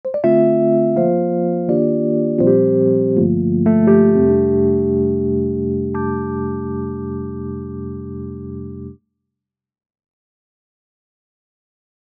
Einige Klänge des E-Pianos haben mich jedenfalls begeistert und der Flügel hat es durchaus in sich.
E-Piano Seven 3
xln_addictive_keys_testbericht_05_e-piano_seven_3.mp3